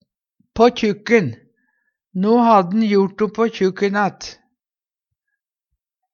på kjukken - Numedalsmål (en-US)